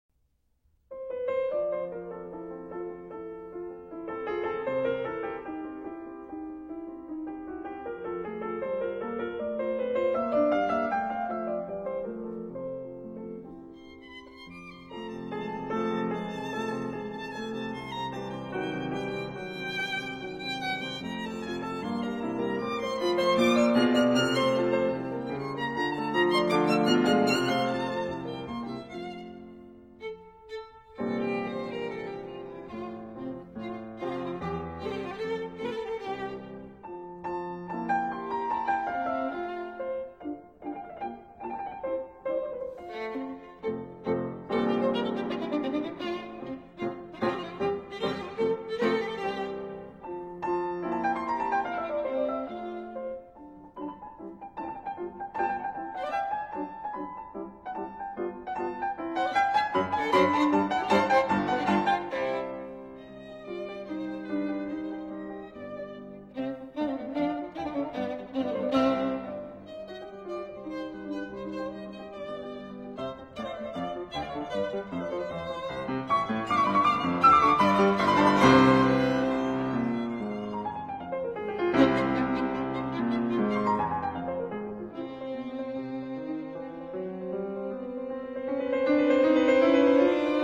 * Ludwig van Beethoven – Sonata For Violin and Piano No.5 In F, Op. 24 ‘Spring’ 4. Rondo. Allegro ma non troppo